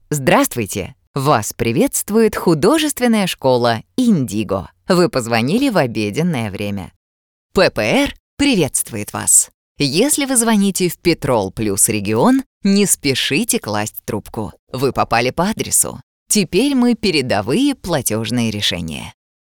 Автоответчик